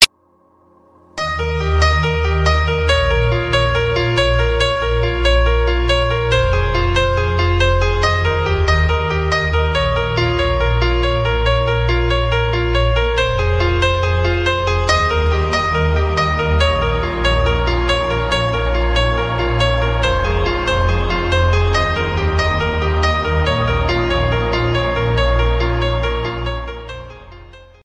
nenapriazhnyi-budil-nik_24517.mp3